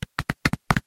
Здесь собраны его знаменитые крики, рычания и другие аудиоэффекты из классических фильмов и мультсериалов.